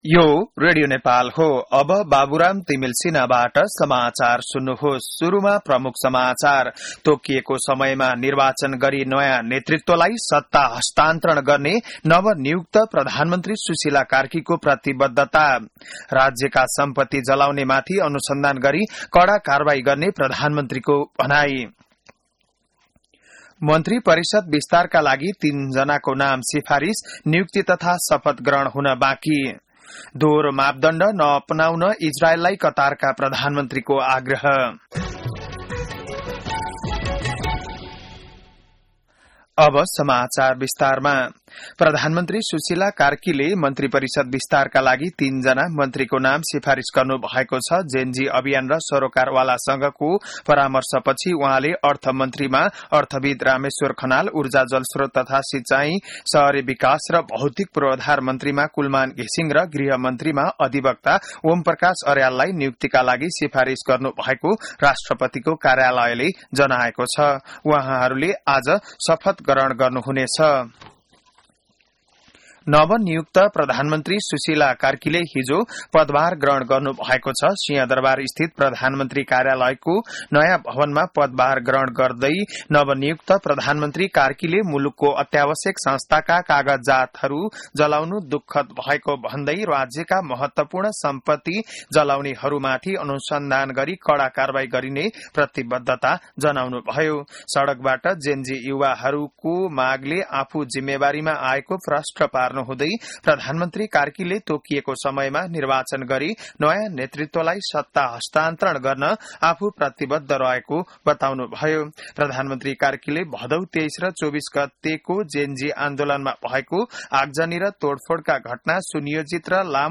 बिहान ९ बजेको नेपाली समाचार : ३० भदौ , २०८२